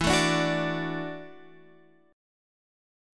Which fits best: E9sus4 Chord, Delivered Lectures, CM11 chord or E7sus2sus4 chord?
E9sus4 Chord